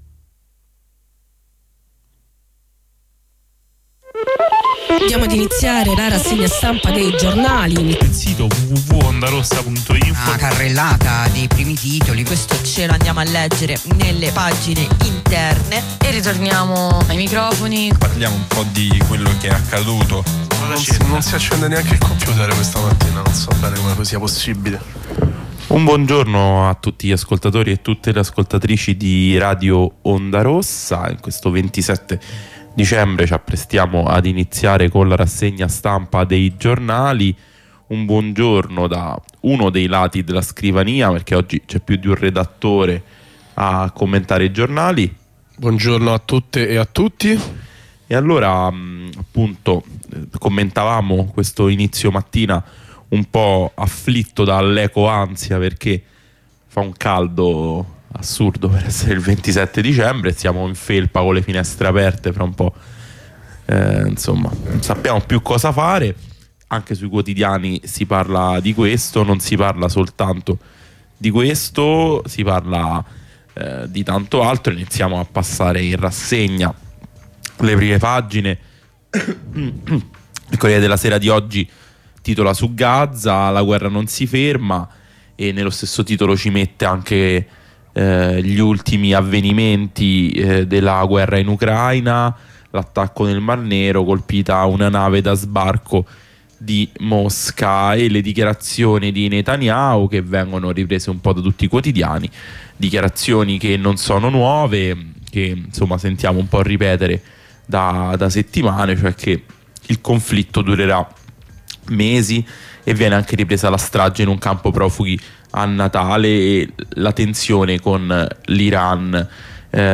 Lettura e commento dei quotidiani